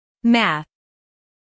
math-stop-us-female.mp3